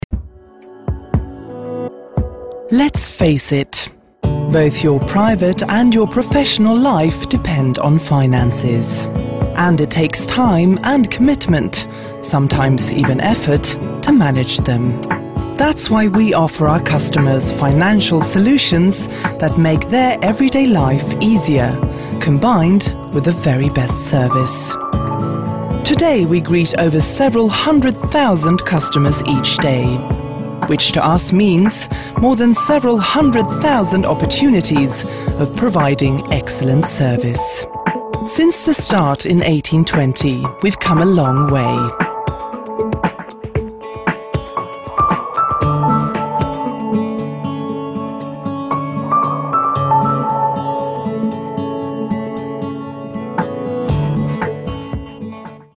Native Swedish, fluent British English. Dark tone, authoritative, sensual, formal.
Sprechprobe: Industrie (Muttersprache):